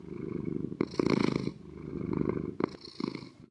minecraft / sounds / mob / cat / purr3.ogg
purr3.ogg